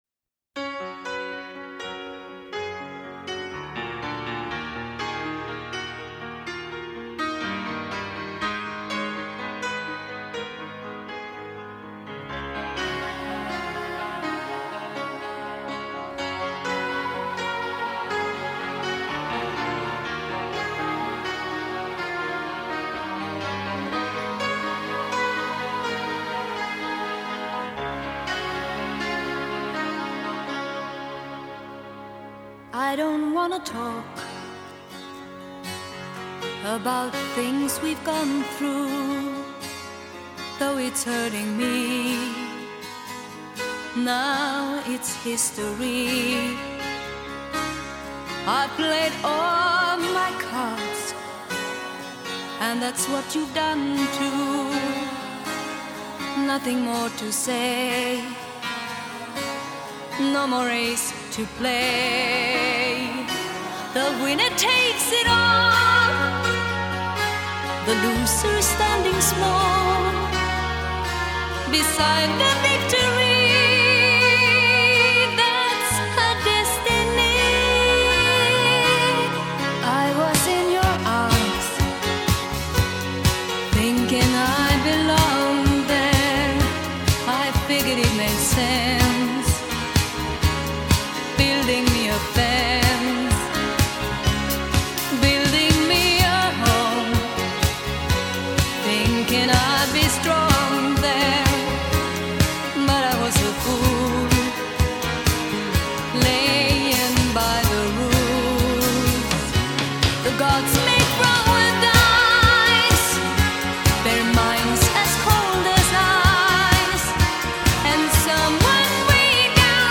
轻松活泼的旋律使人回味无穷，配器和谐自然，主旋律音乐与伴奏音乐相互辉映，合而为一，多元化的风格也是成功的一大元素。